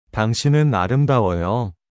• 당신은 (dangsineun): Pronuncie “dang-shi-neun”. Certifique-se de alongar levemente o som do “shi”.
• 아름다워요 (areumdawoyo): Pronuncie “a-reum-da-wo-yo”. Dê ênfase suave no “da”.